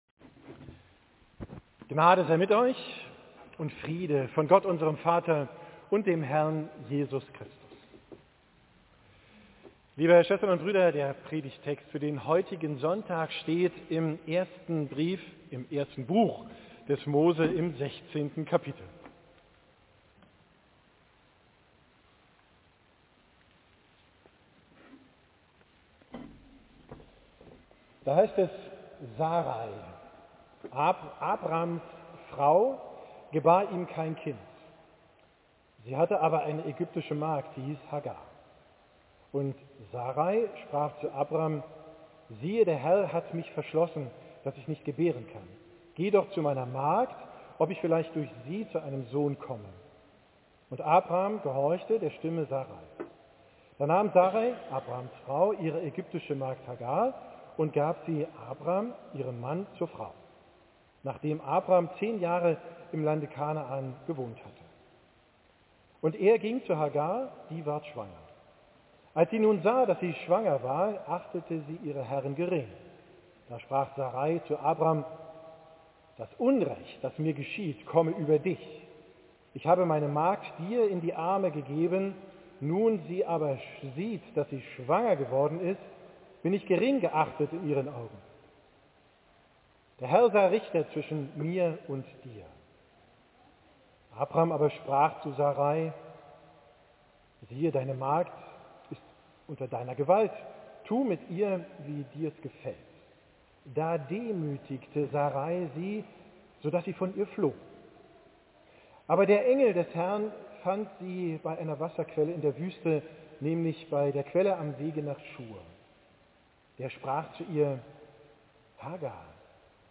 Gottesdienst mit Vorstellung der Konfrimanden am 14. IV 2024, Predigt